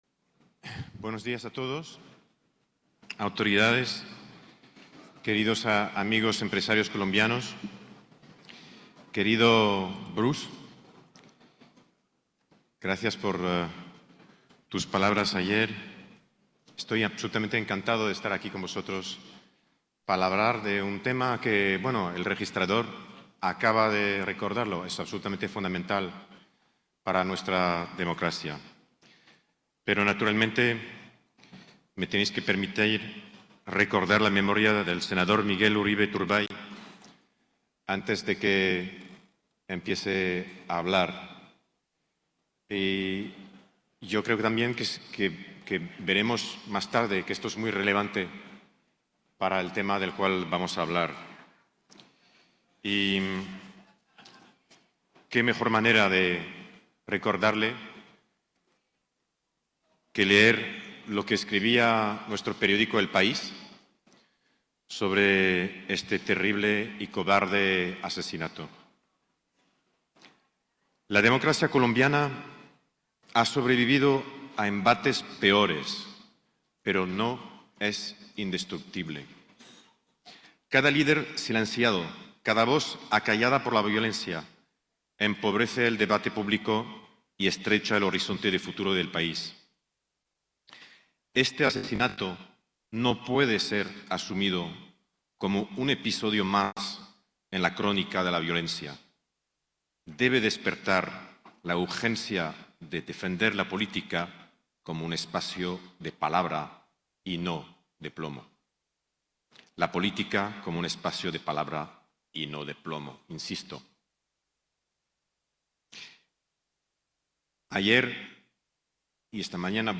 Durante la Asamblea de la ANDI en Cartagena, Joseph Oughourlian, CEO de Prisa, habló sobre los riesgos de las redes sociales y la necesidad de contrastar y verificar la información.